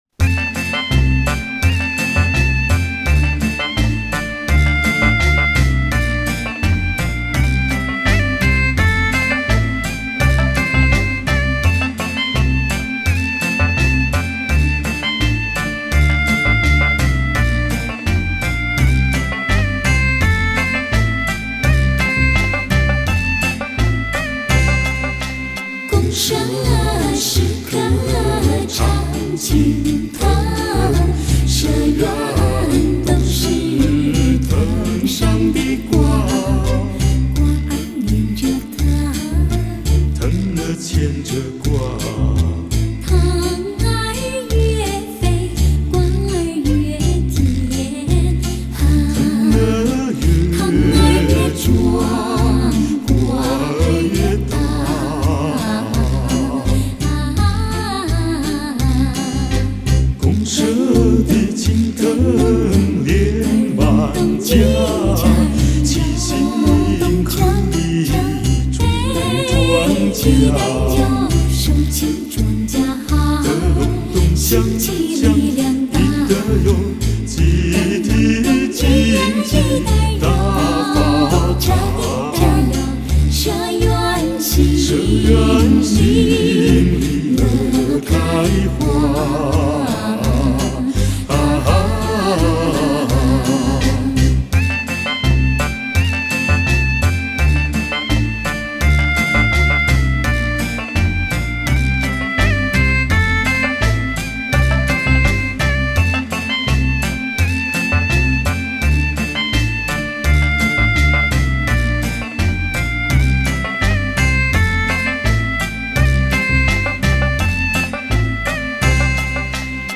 男女声三重唱
运用通俗唱法、美声唱法和民族唱法重新加以演绎